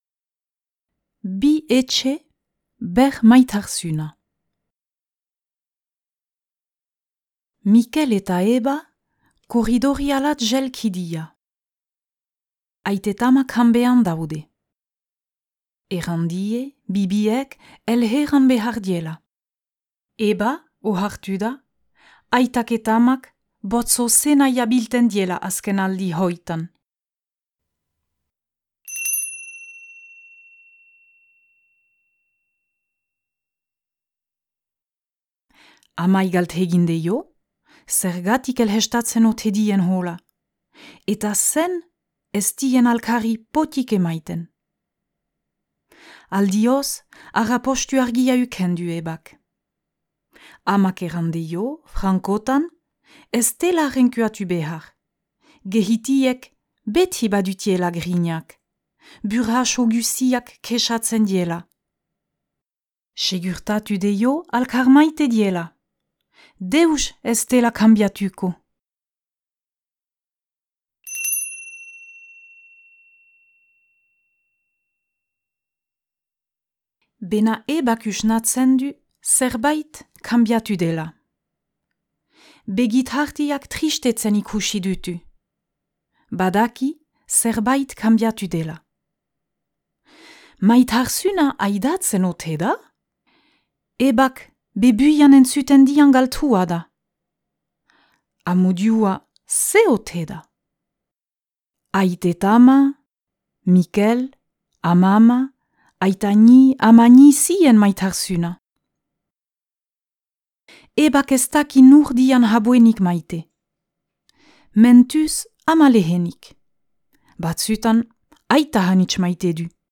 Bi etxe, ber maitarzüna - Zubereraz - ipuina entzungai